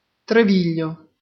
Treviglio (Italian: [treˈviʎʎo]
It-Treviglio.ogg.mp3